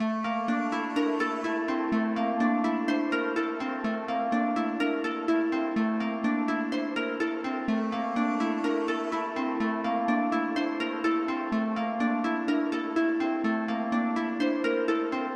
Tag: 125 bpm Trap Loops Strings Loops 2.58 MB wav Key : A